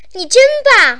女孩说你真棒音效_人物音效音效配乐_免费素材下载_提案神器